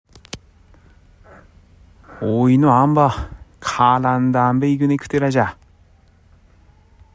津軽方言：古代の発音
津軽方言のを聞くと、古代日本語の音声の一部が、現代の津軽方言に受け継がれていることが分かります。